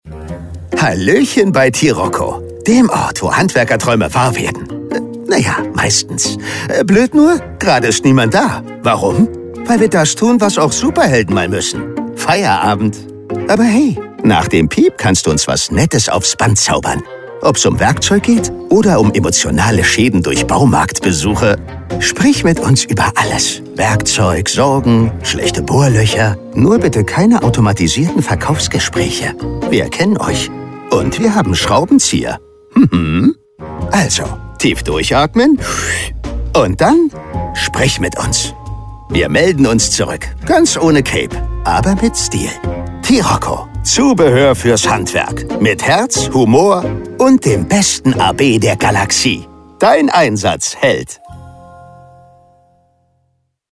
Telefonansage mit Dennis Schmidt-Foß der deutschen Stimme von Deadpool Star Ryan Reynolds
Telefonansagen mit echten Stimmen – keine KI !!!
So wünschte sich unser Kunde TIROCCO zum Beispiel eine freche und pfiffige Ansage im lockeren Stil von DEADPOOL.
Schwups haben wir, Dennis Schmidt-Foß, den deutschen Synchronsprecher von Ryan Reynolds ins Studio eingeladen und hier ist sie, die neue Ansage von TIROCCO: